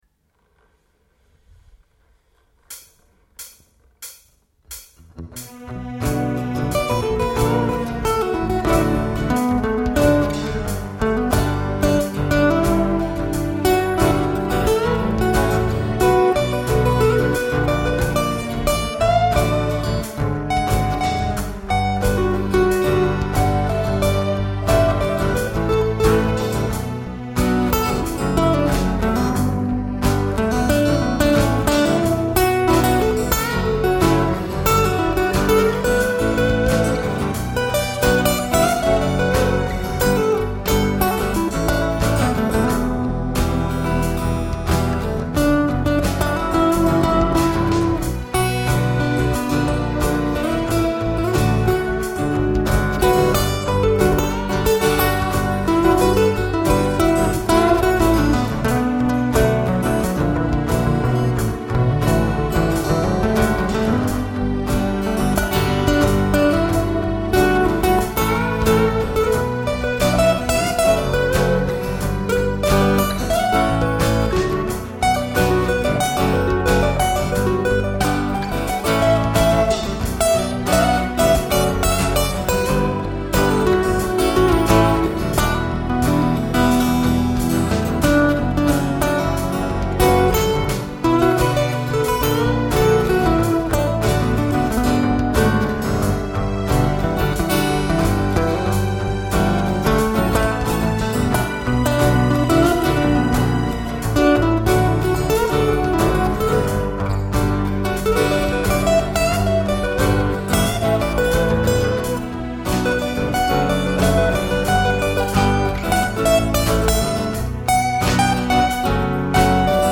Raw-songs
World music
Jazz